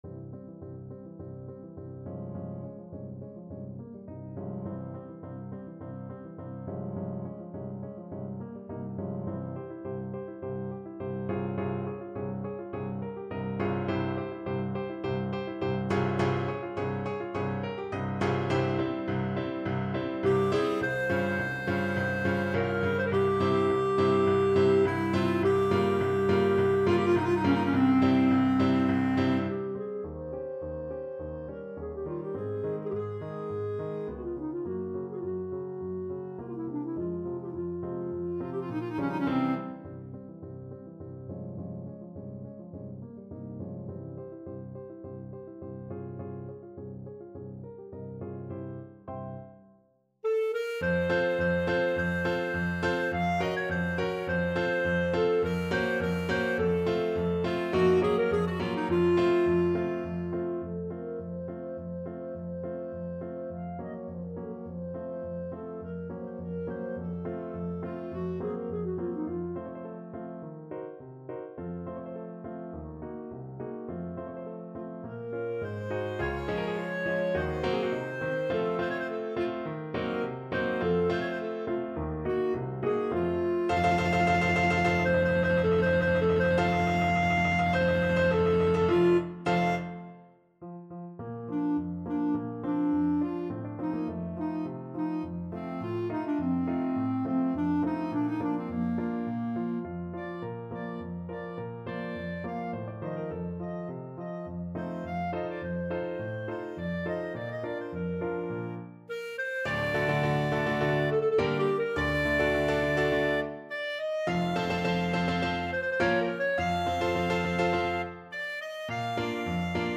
Paso doble =104
Bb4-Bb6
2/4 (View more 2/4 Music)
Classical (View more Classical Clarinet Music)